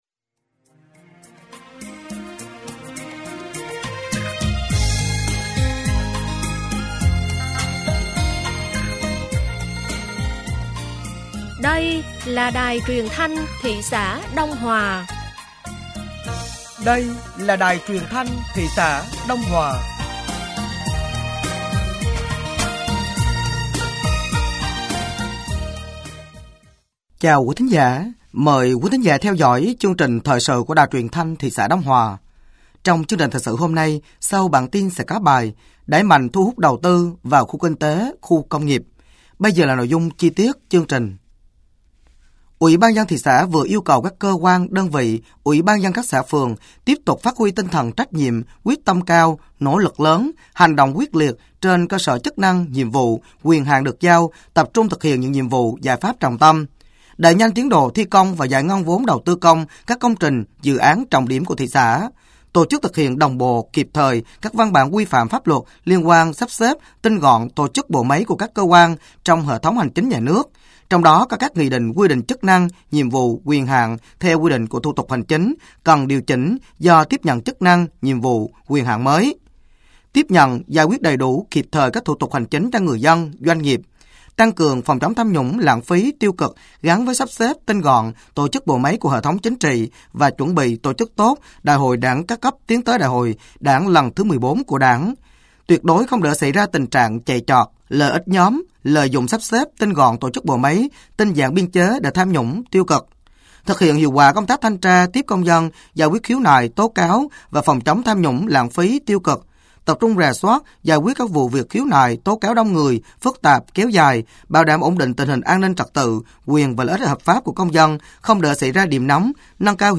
Thời sự tối ngày 11 và sáng ngày 12tháng 4 năm 2025